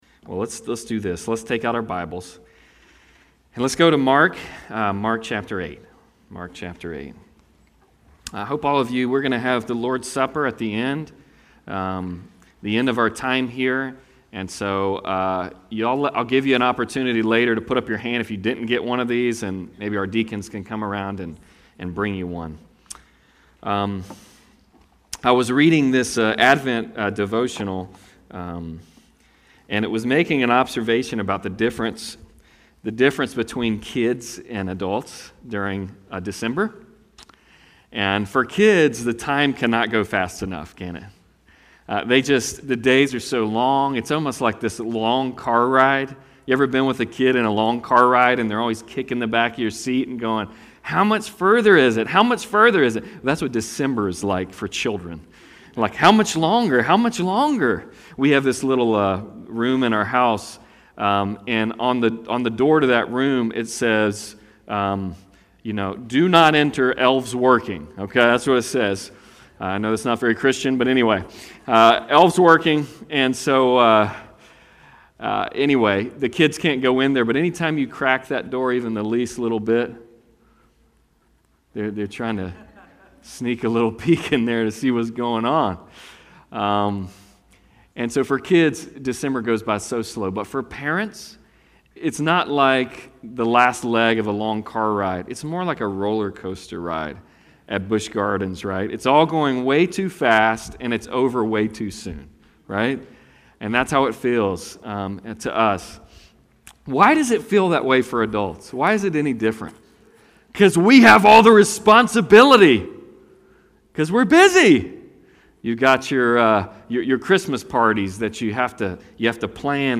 Passage: Mark 8:22-33 Service Type: Sunday Service